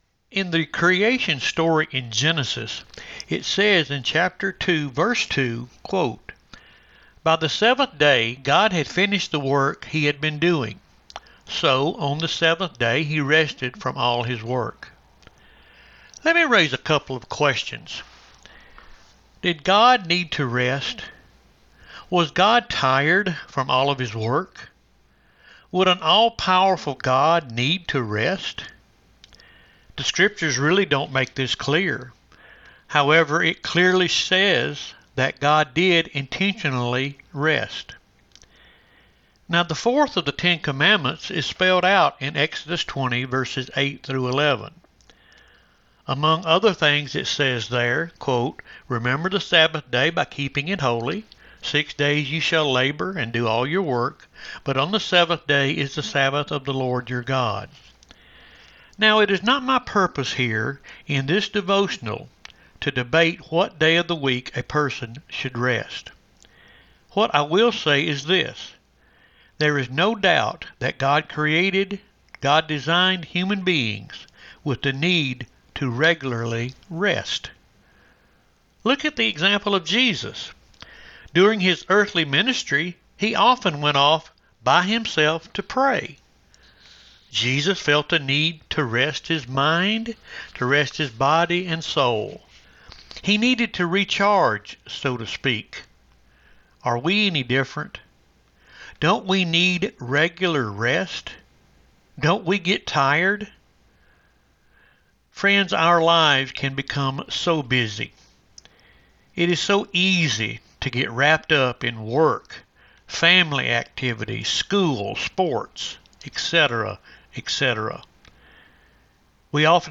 Friday 8/19 Devotion – Lyerly United Methodist Church
Church Programs 0